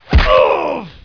HIT02.WAV